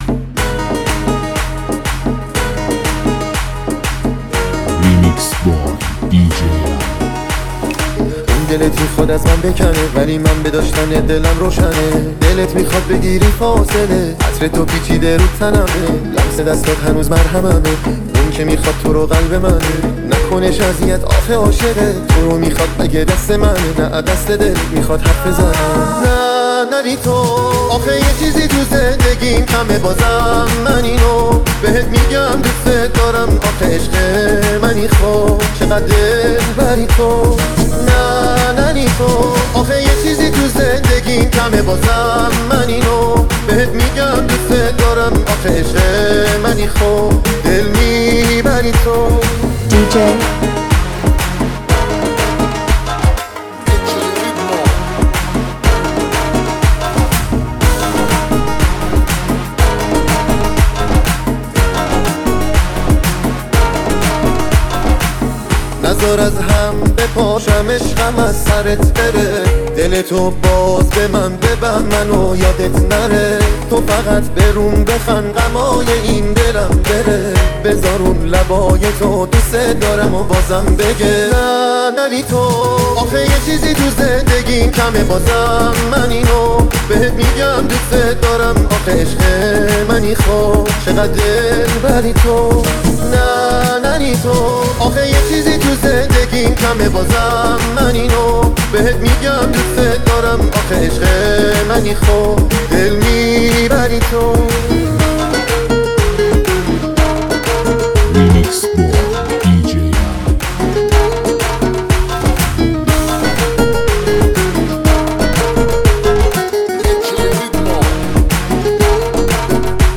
لذت بردن از موسیقی پرانرژی و بیس دار، هم‌اکنون در سایت ما.